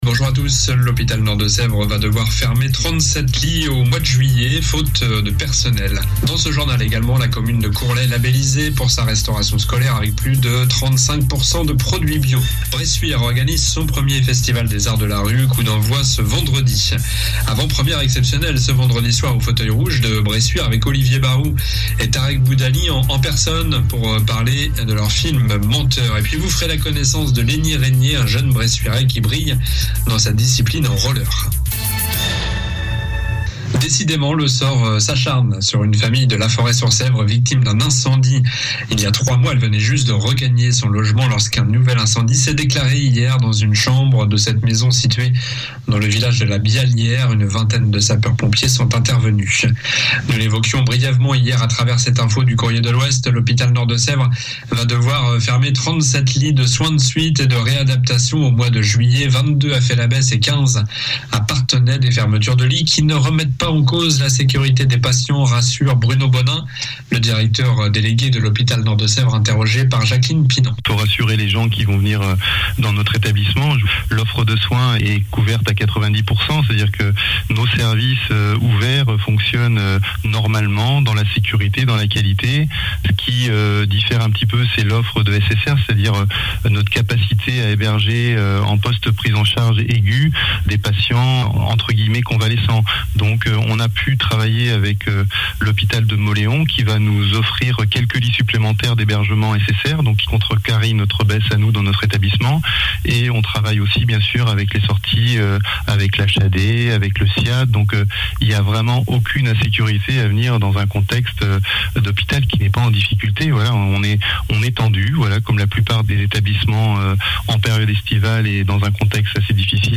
Journal du vendredi 1er juillet (midi)